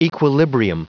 Prononciation du mot equilibrium en anglais (fichier audio)